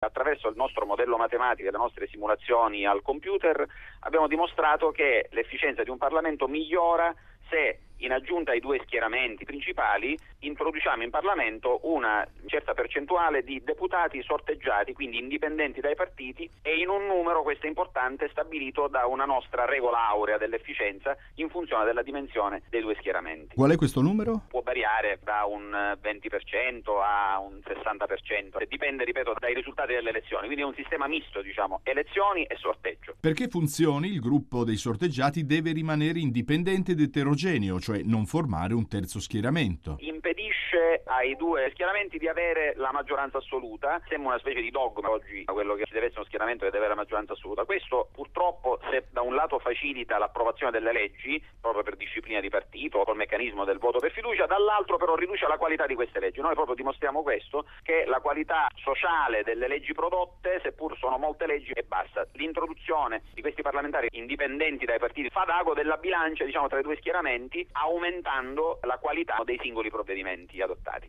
Intervista radiofonica su Radio Rai GR3